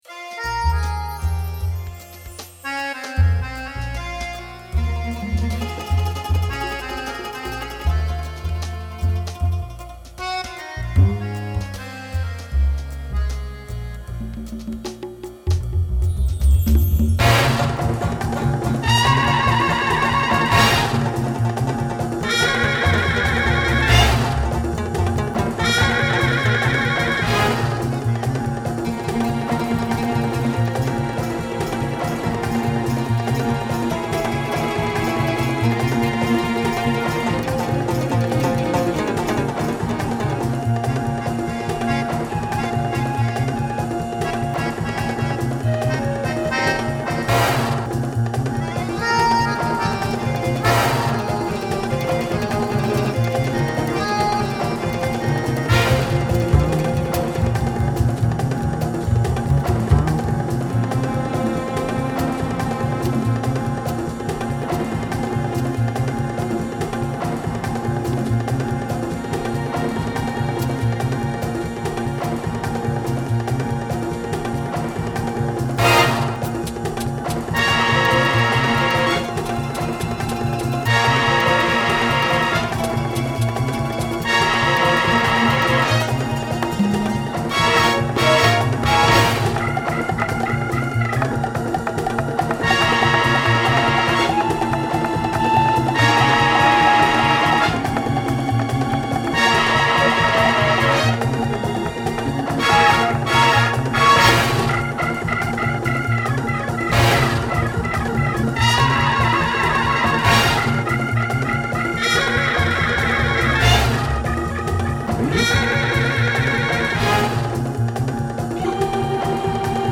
suspense
banda sonora
melodía
Sonidos: Música